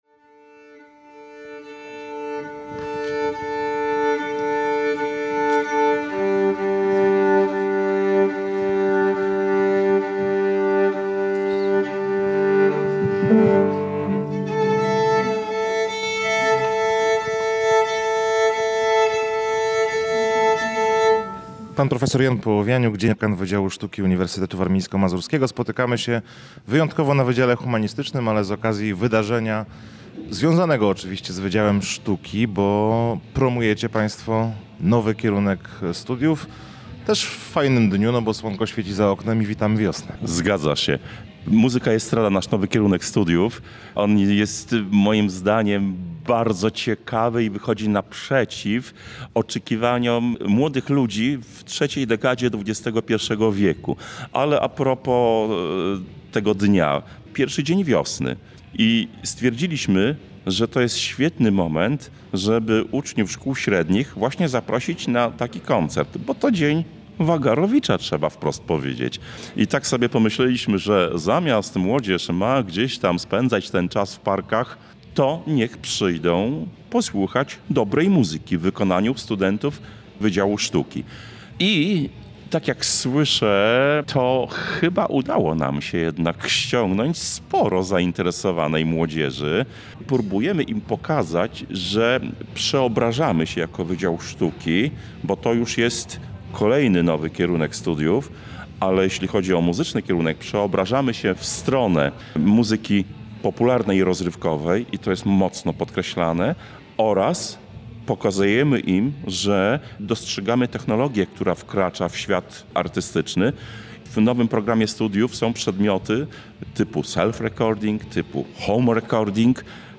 Muzyka i estrada – to nowy kierunek studiów, który od roku akademickiego 2025/2026 znajdzie się w ofercie kształcenia Wydziału Sztuki Uniwersytetu Warmińsko-Mazurskiego w Olsztynie. To także tytuł koncertu, którym wiosnę przywitali obecni studenci edukacji artystycznej w zakresie sztuki muzycznej oraz produkcji muzycznej i realizacji dźwięku. W piątek 21 marca wokaliści i instrumentaliści z UWM zaprezentowali swoje talenty uczniom olsztyńskich liceów w Auli Teatralnej Wydziału Humanistycznego.
2103-MA-Muzyka-i-Estrada-koncert.mp3